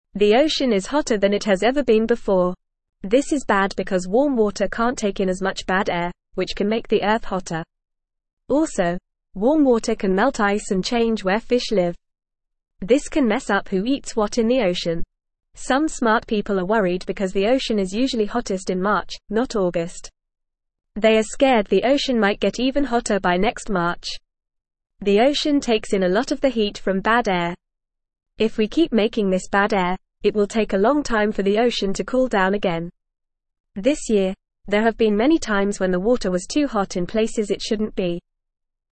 Fast
English-Newsroom-Beginner-FAST-Reading-The-Ocean-is-Getting-Hotter-and-Thats-Bad.mp3